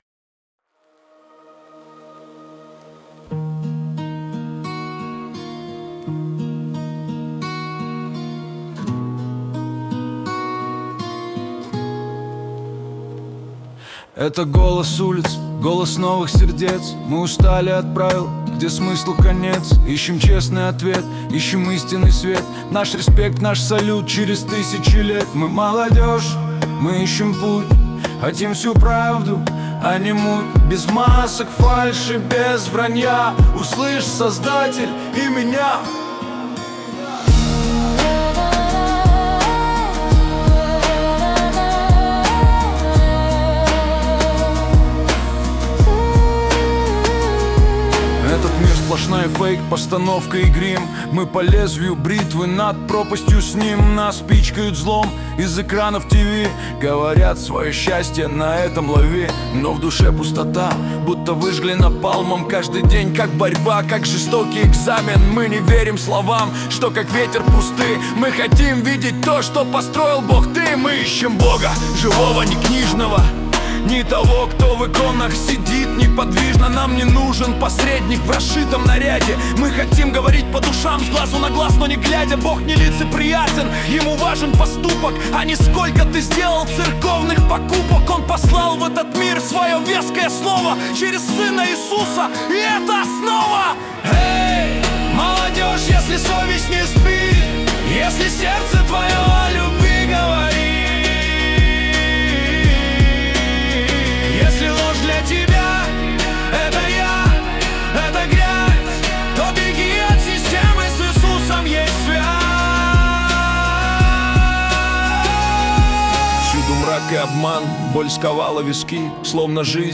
песня ai
360 просмотров 1095 прослушиваний 99 скачиваний BPM: 84